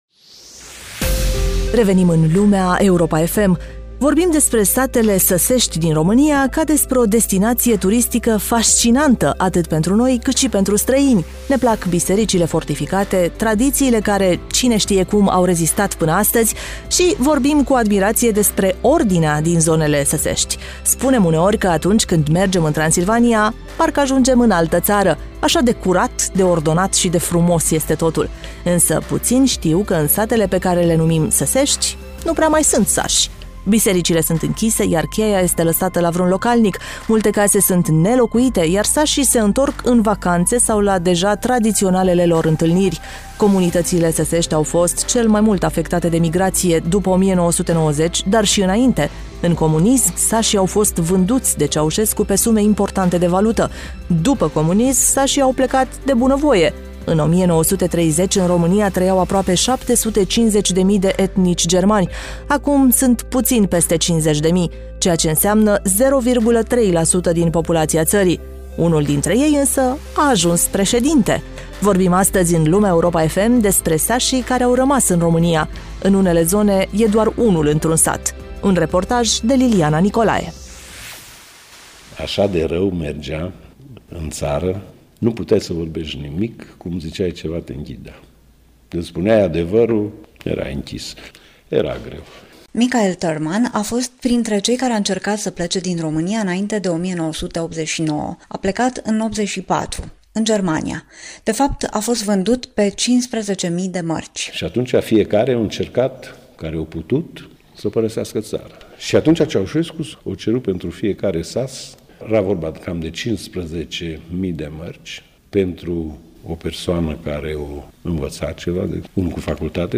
REPORTAJ: Sate săsești fără sași